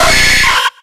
Cries
NIDORINO.ogg